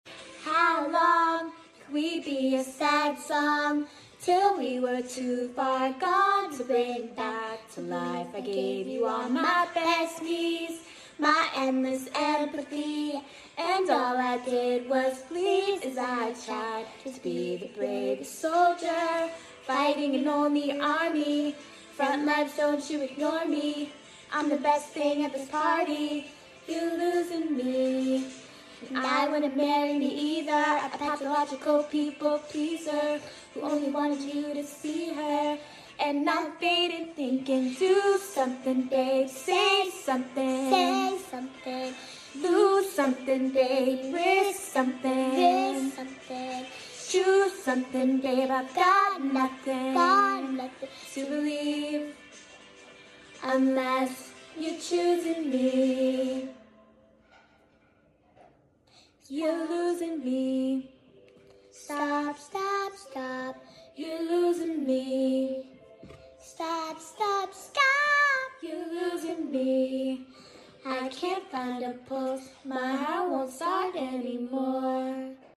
watching her sing it live for the first time for Melbourne